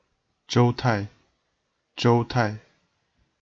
pronunciation) (died c. 223), courtesy name Youping, was a military general serving under the warlord Sun Quan during the late Eastern Han dynasty and early Three Kingdoms period of China.